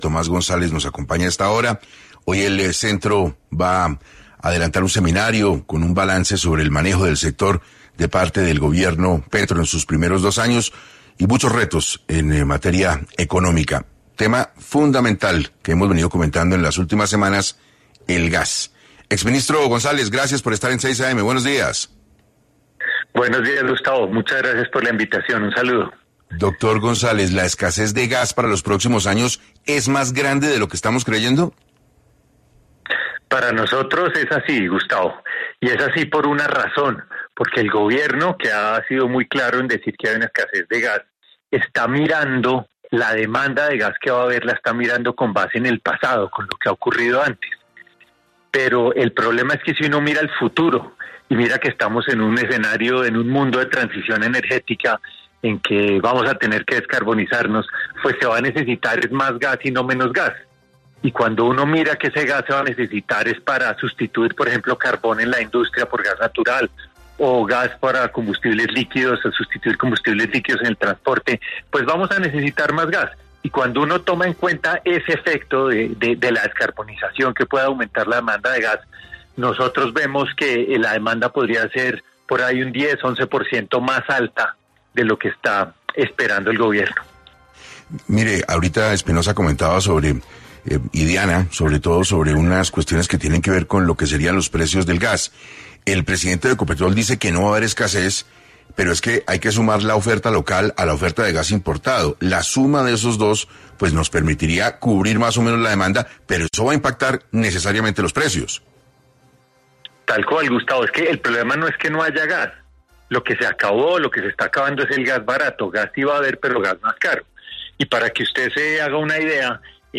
En 6AM Hoy por Hoy de Caracol Radio estuvo Tomás González, exministro de Minas y actual director del Centro Regional de Estudios de Energía, para hablar sobre cómo asumiría Colombia la escasez de gas que se proyecta para los próximos años.